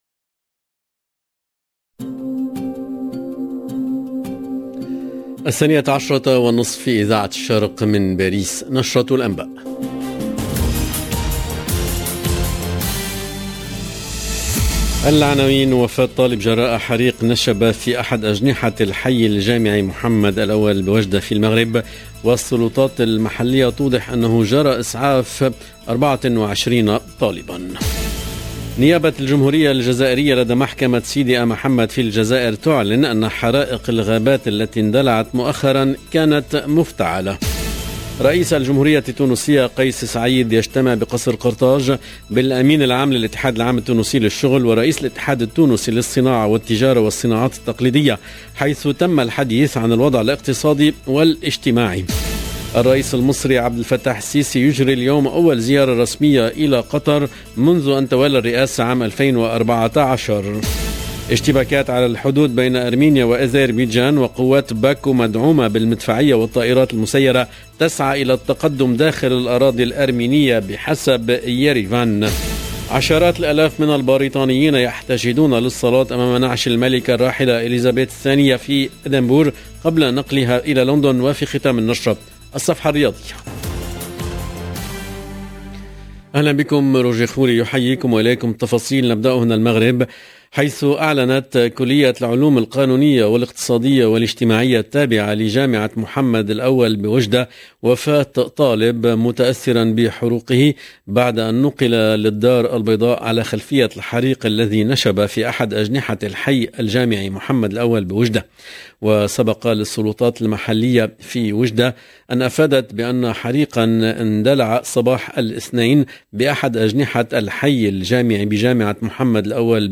LE JOURNAL EN LANGUE ARABE DE MIDI 30 DU 13/09/22